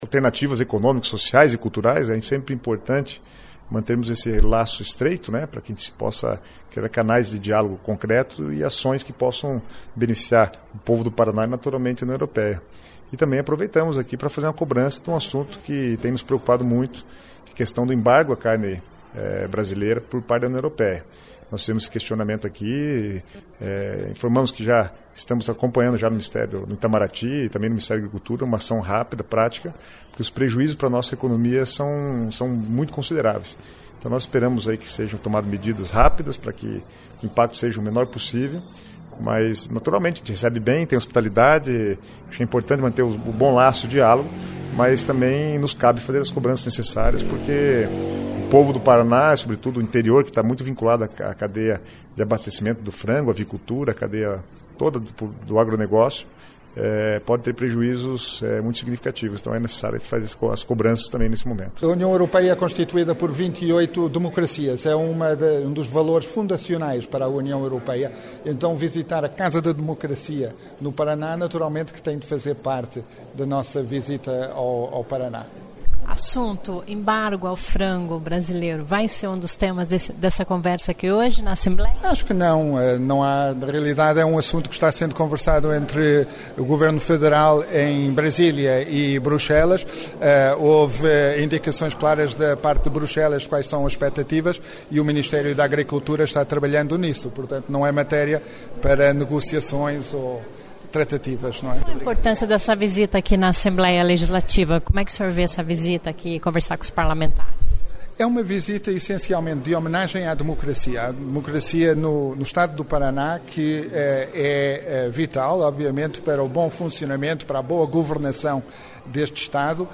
Ouça as entrevistas com o parlamentar e com o representante da delegação, João Gomes Cravinho, embaixador de Portugal.